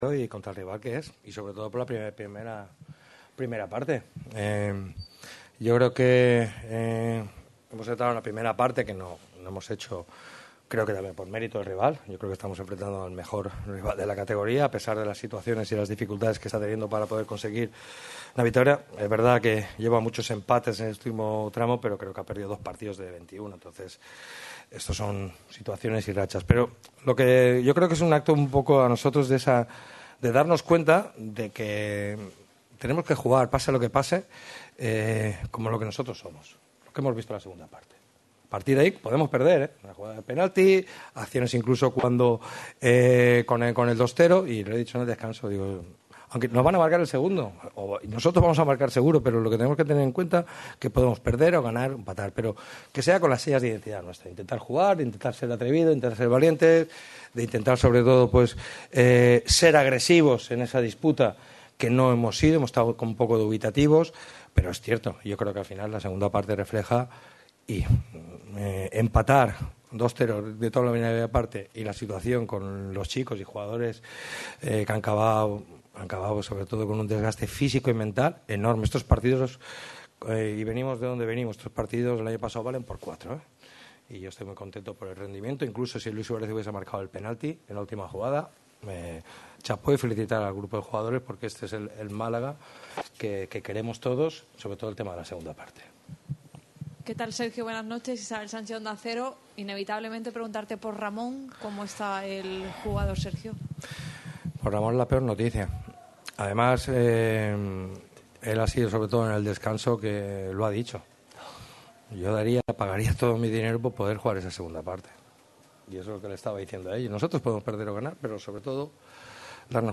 Después de lograr este gran empate, Sergio Pellicer compareció ante los medios de comunicación para analizar las claves del heroico empate. Respondió a las numerosas bajas del próximo partido ante el Cádiz. No ha dudado en alabar el trabajo del equipo en los últimos 45 minutos.